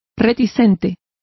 Also find out how reticente is pronounced correctly.